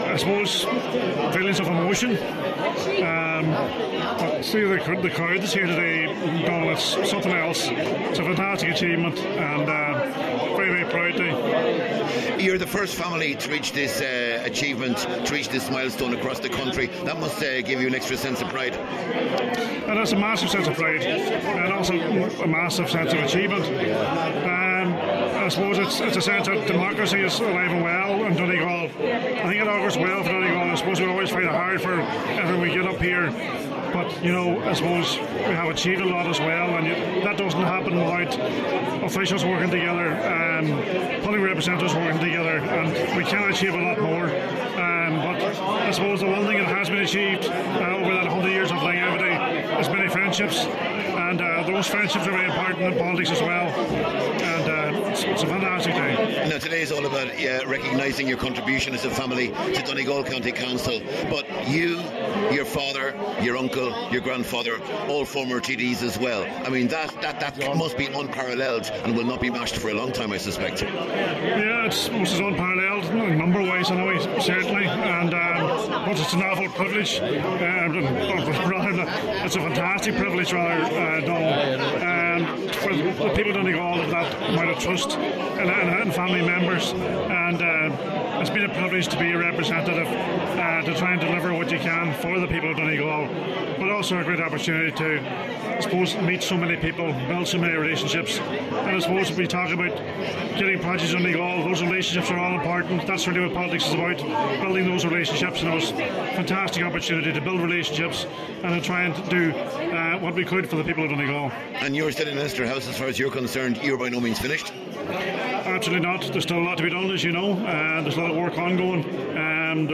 He told Highland Radio News that today’s reception is a proud day for the family, but more importantly, the milestone shows the strength of democracy in Donegal: